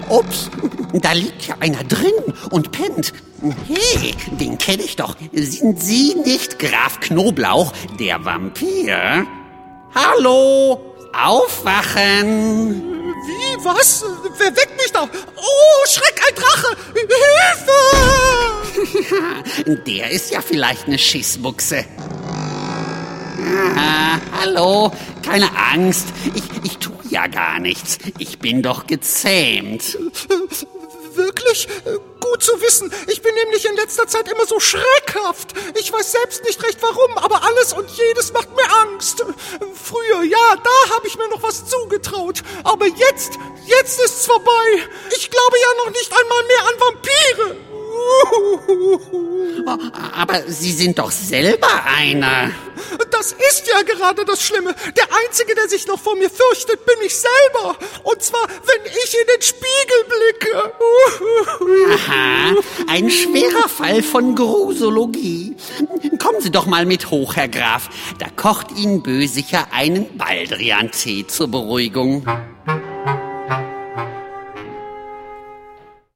Ravensburger Ritter Rost 02 - Der Vampir ✔ tiptoi® Hörbuch ab 3 Jahren ✔ Jetzt online herunterladen!